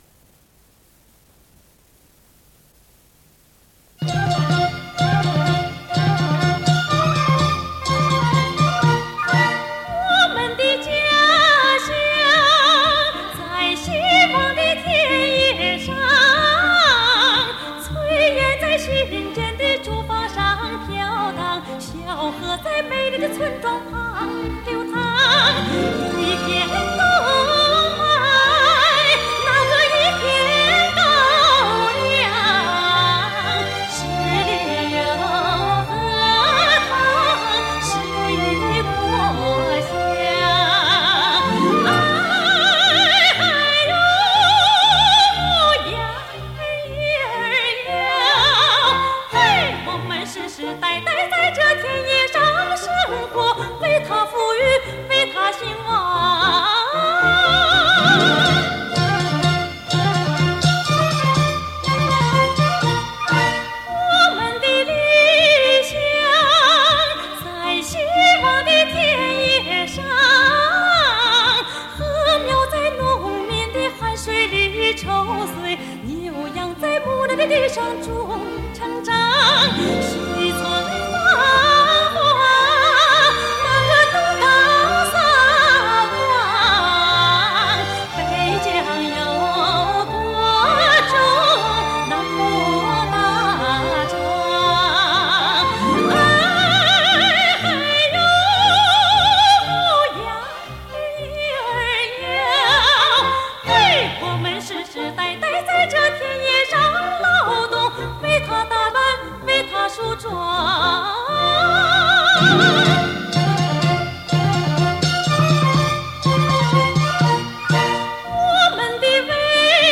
女高音）独唱